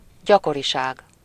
Ääntäminen
Ääntäminen Tuntematon aksentti: IPA: /fʁe.kɑ̃s/ Haettu sana löytyi näillä lähdekielillä: ranska Käännös Ääninäyte 1. gyakoriság 2. ismétlődés 3. frekvencia Suku: f .